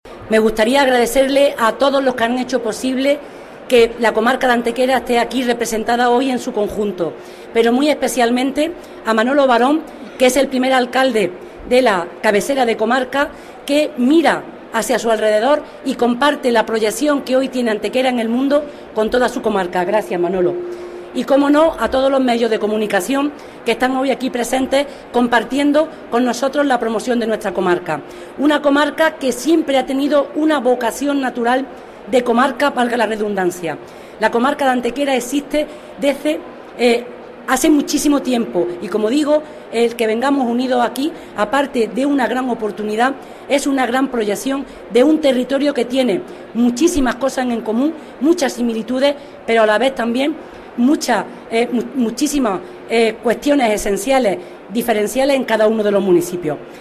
Rueda de prensa en FITUR de la iniciativa conjunta de promoción de la Comarca de Antequera
En la misma, desarrollada en el expositor de Málaga-Costa del Sol, han estado presentes los Alcaldes y Alcaldesas de Alameda, Antequera, Archidona, Campillos, Casabermeja, Cuevas de San Marcos, Villanueva de Tapia, Villanueva del Trabuco y Valle de Abdalajís.
Cortes de voz